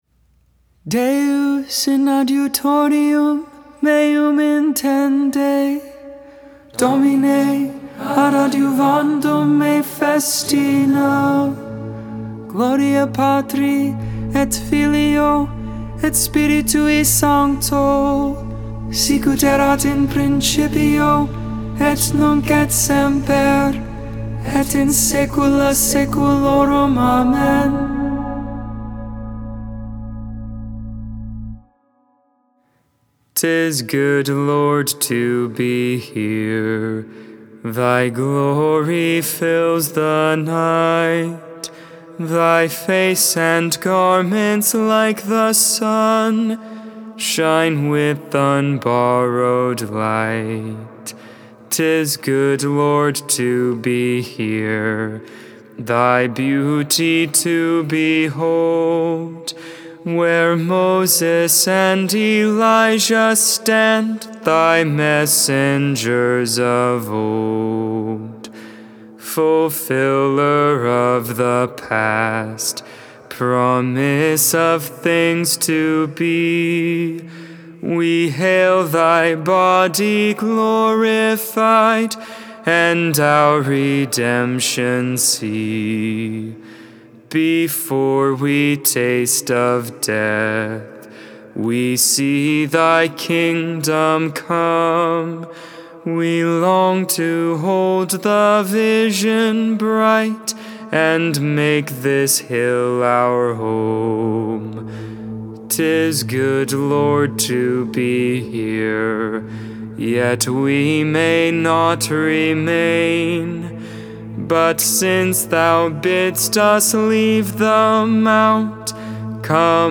3.12.22 Vespers, Saturday Evening Prayer